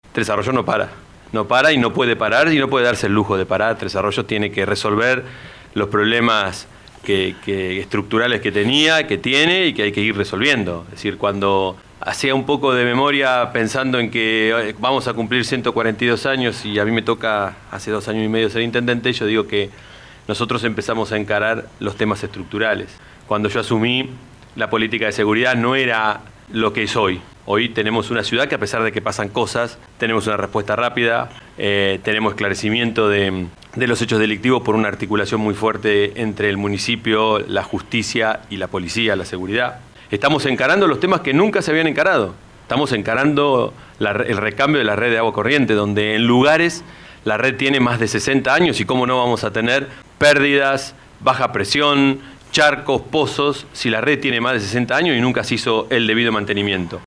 Este martes, el intendente Pablo Garate, en los estudios de LU 24, habló sobre el presente político y económico de la ciudad, asegurando que “Tres Arroyos no para, no puede darse el lujo de parar”.